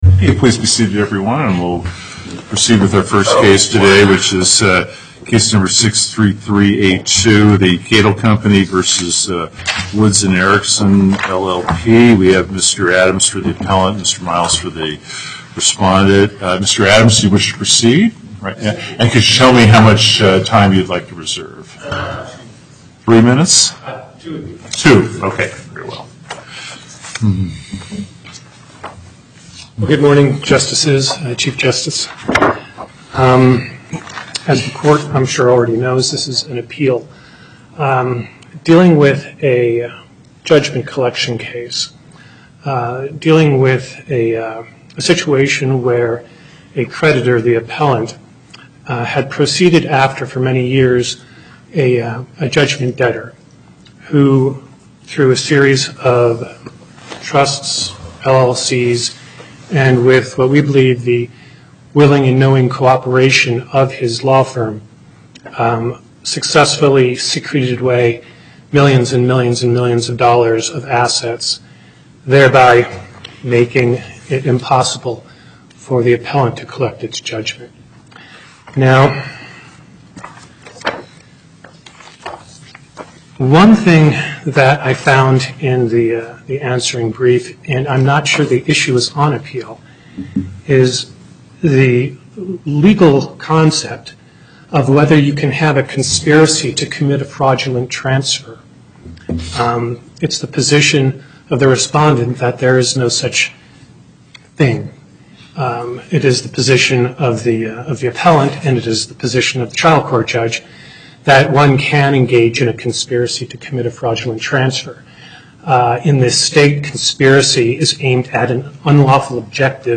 Location: Carson City Before the En Banc Panel, Chief Justice Gibbons Presiding